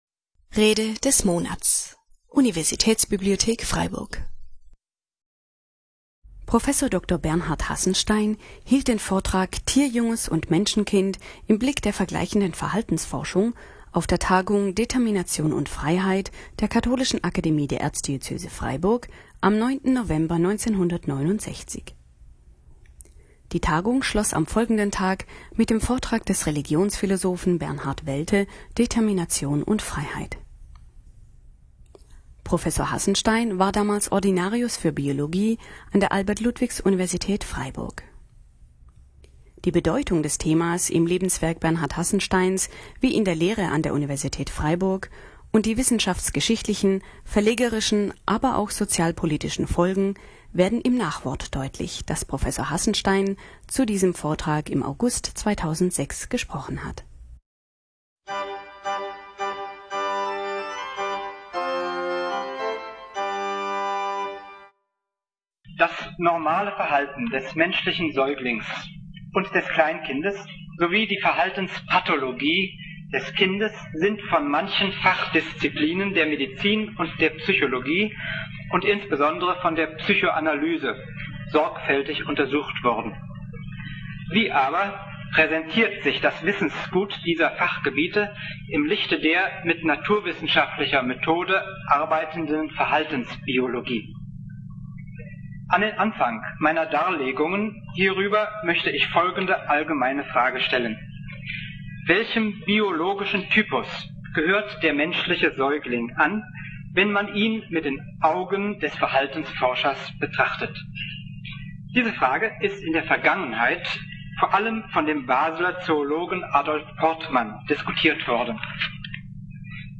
Tierjunges und Menschenkind im Blick der vergleichenden Verhaltensforschung (1969) - Rede des Monats - Religion und Theologie - Religion und Theologie - Kategorien - Videoportal Universität Freiburg